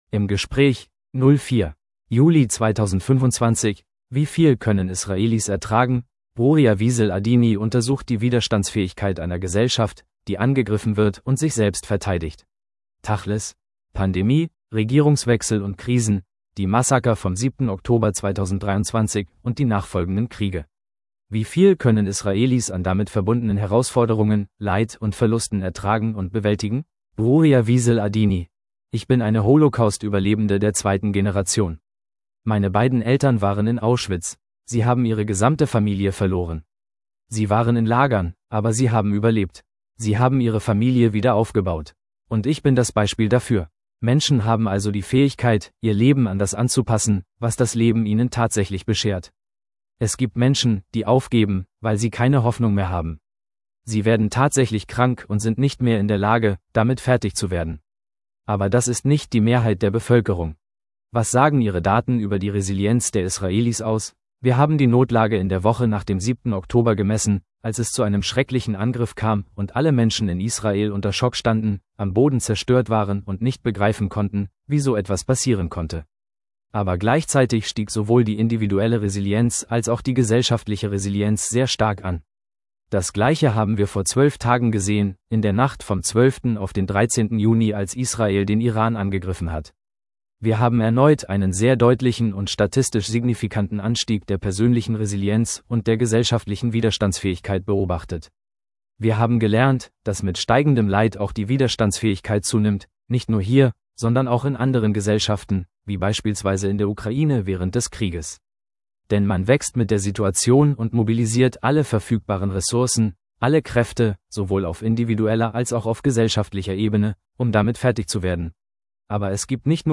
im Gespräch 04.